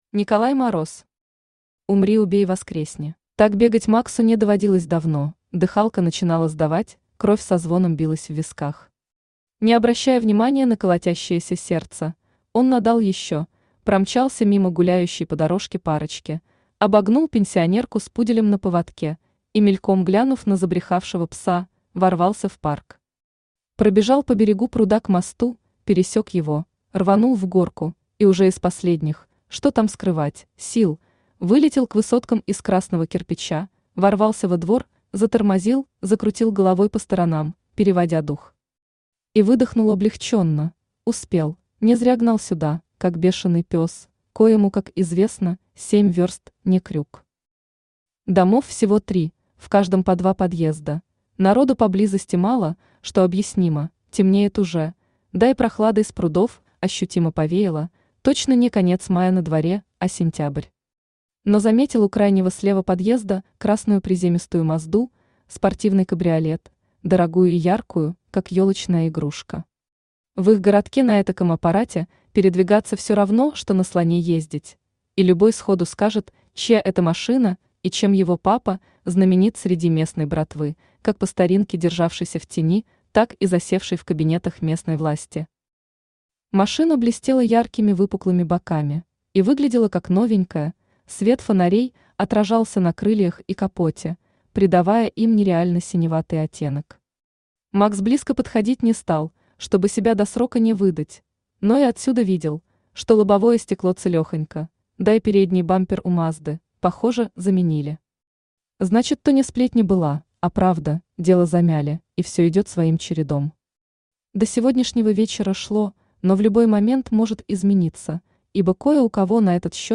Аудиокнига Умри Убей Воскресни | Библиотека аудиокниг
Aудиокнига Умри Убей Воскресни Автор Николай Мороз Читает аудиокнигу Авточтец ЛитРес.